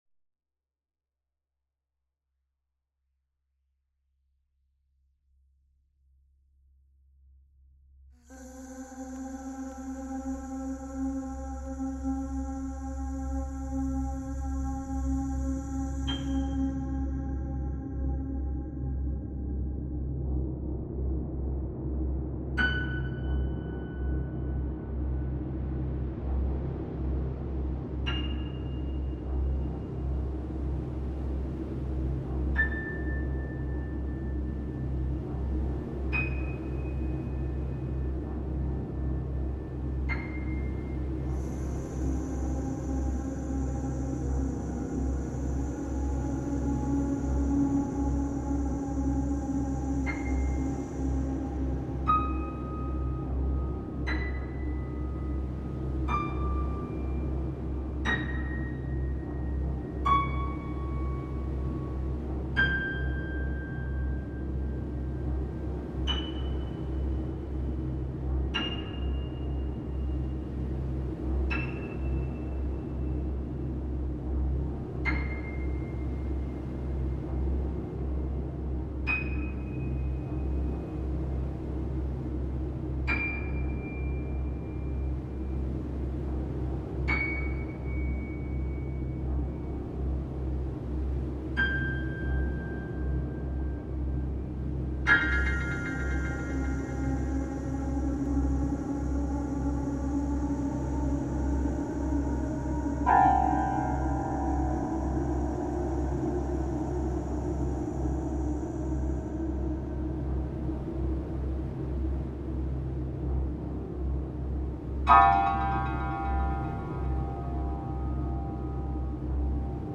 instrumental album
but tending towards soundtrack and orchestral music.